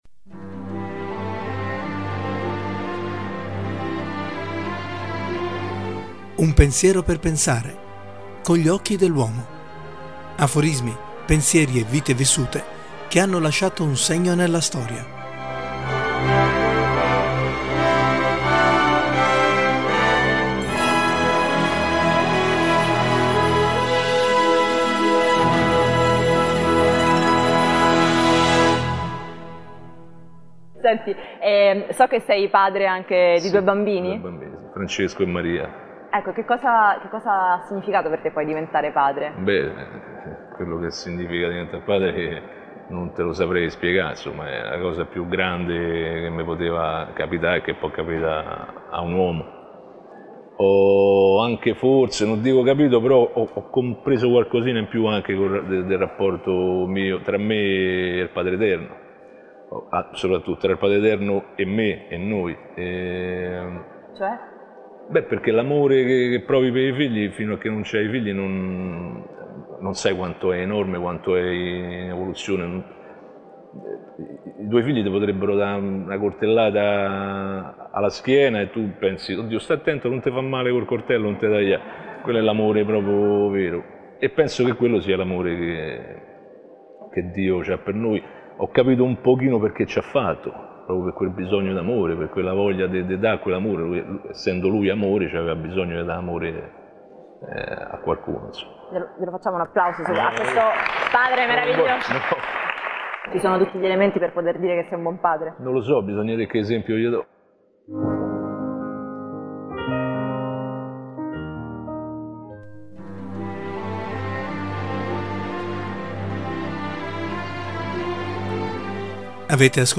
Un Pensiero per Pensare 105: (Con gli occhi dell’uomo) Aforismi, pensieri e vite vissute, che hanno lasciato un segno nella storia. In questo numero: Intervista all’attore Ricky Memphis, …penso di